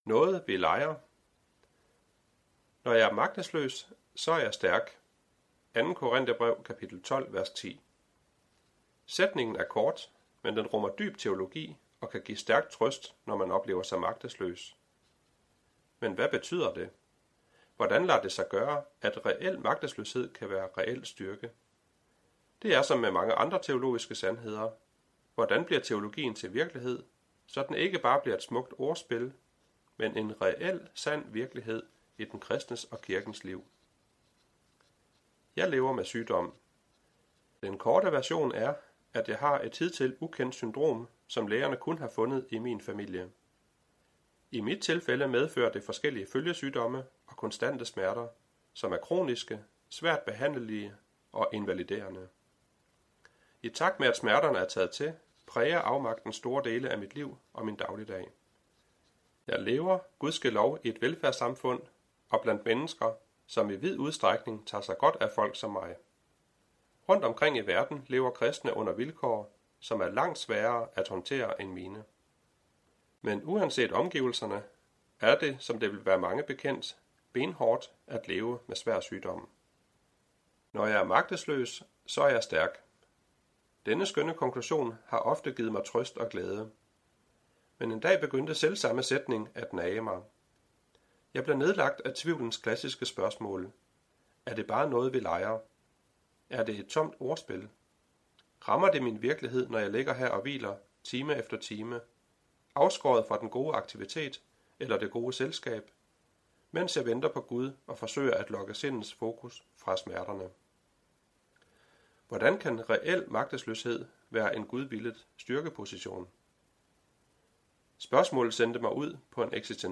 Hør et uddrag af Magtesløs styrke
Lydbog